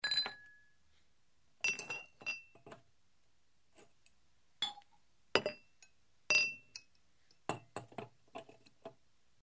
BOTELLAS
Tonos gratis para tu telefono – NUEVOS EFECTOS DE SONIDO DE AMBIENTE de BOTELLAS
botellas.mp3